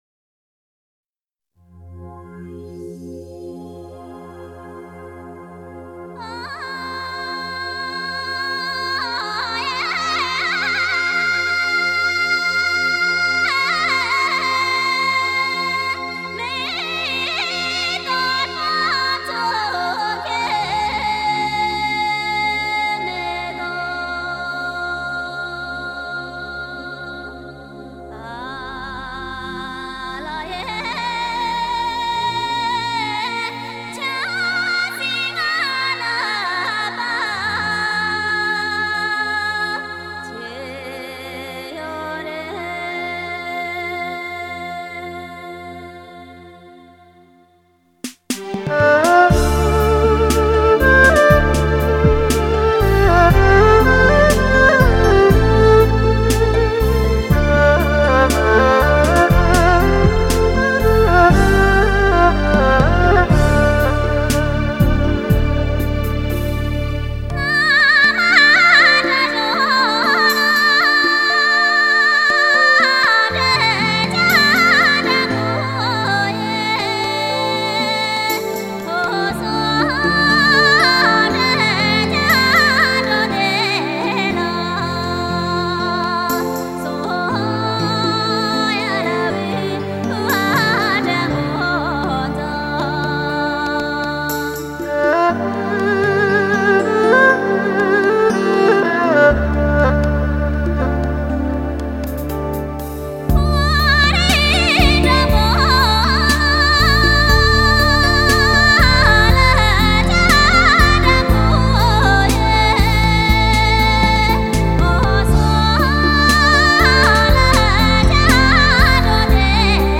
而集中体现在音乐上的，则是藏族的酒歌。